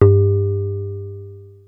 JUP.8 G2   3.wav